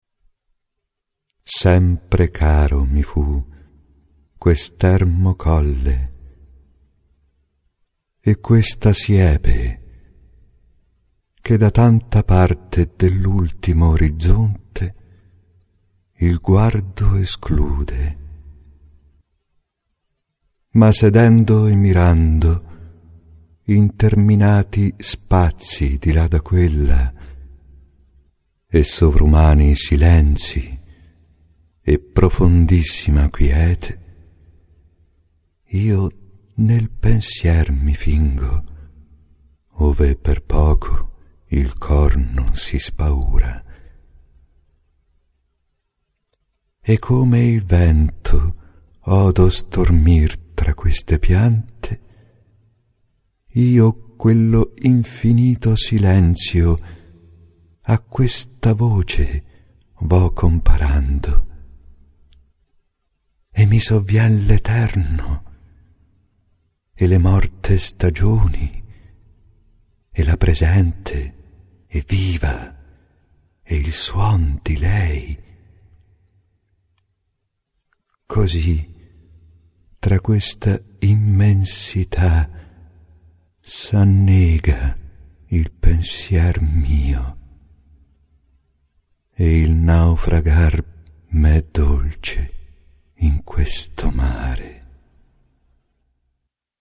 recitato.mp3